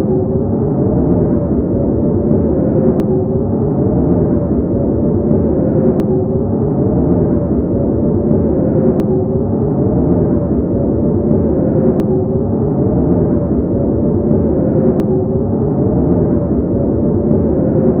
grave.ogg